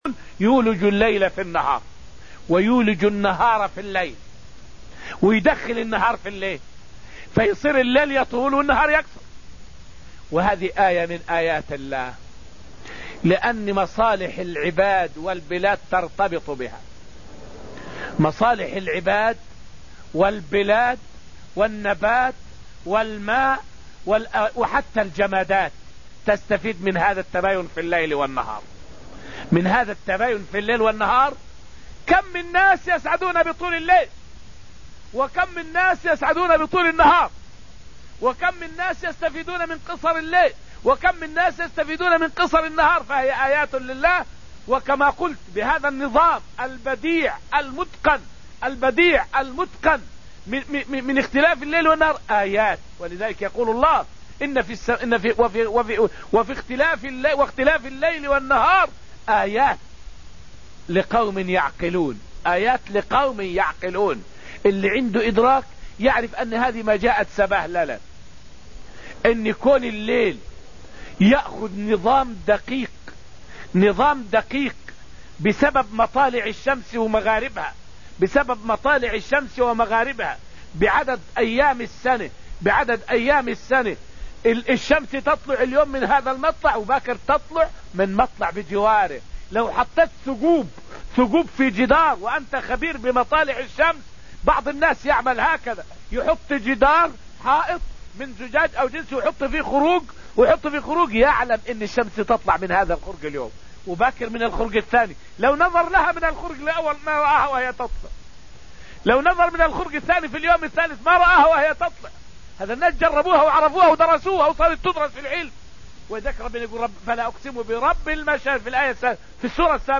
الدرس التاسع من دروس تفسير سورة الحديد من دروس المسجد النبوي تفسير الآيات من قوله تعالى {وما لكم لا تؤمنون بالله والرسول} الآية إلى قوله تعالى {هو الذي ينزل على عبده آيات بينات} الآية 9.